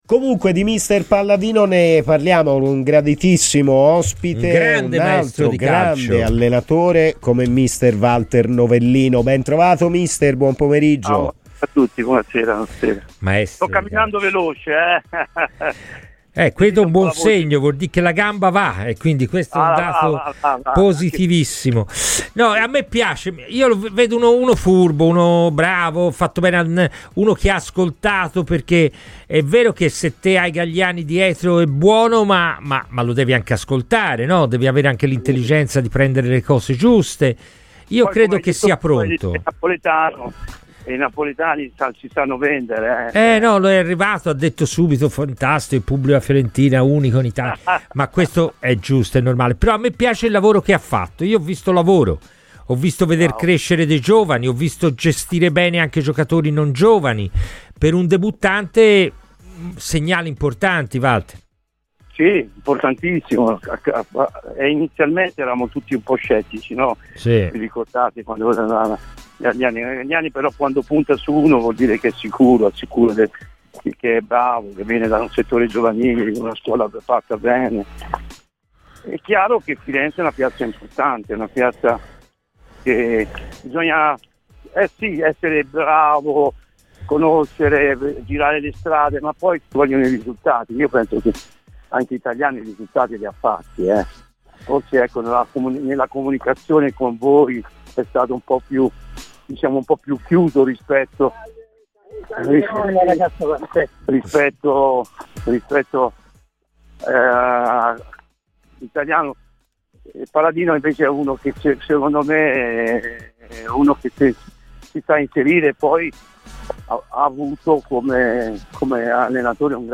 Walter Novellino, ex allenatore della Fiorentina, ha parlato oggi a Radio Firenzeviola durante 'Palla al centro'.